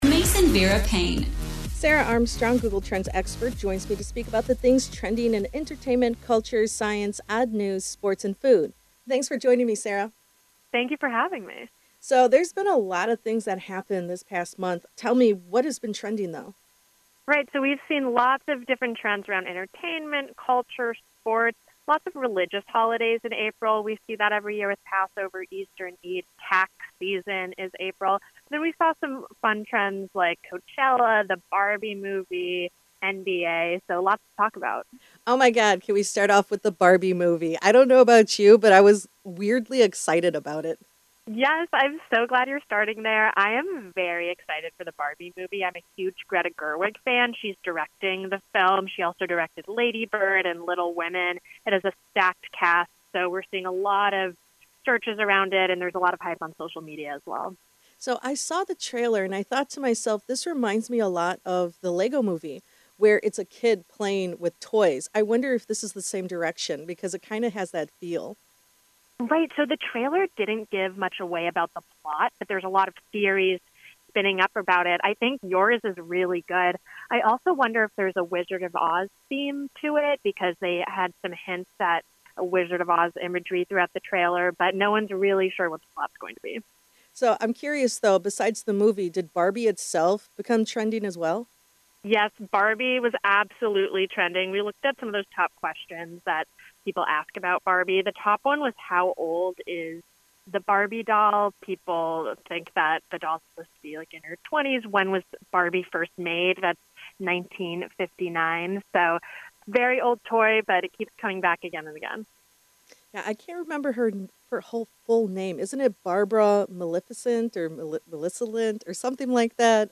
Announcer